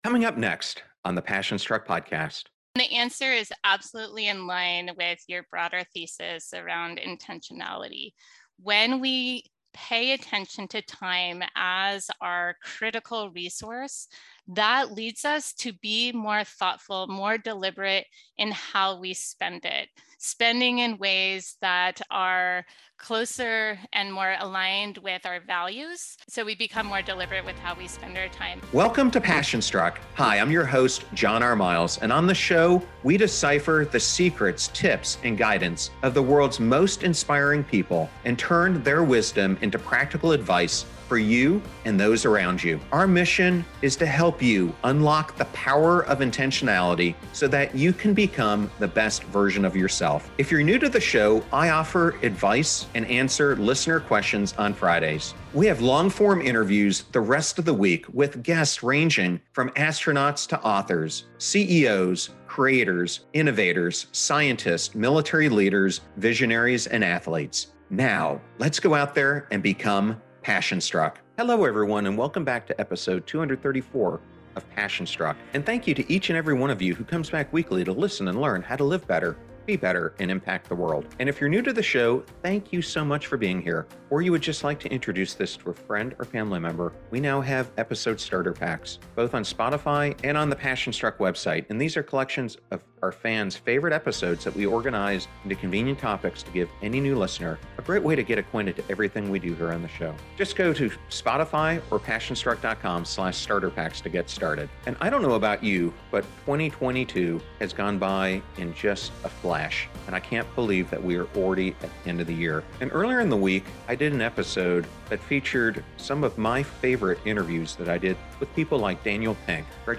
Today’s special episode of the Passion Struck podcast highlights some of our best interviews from 2022, showcasing the power of intentional behavior change.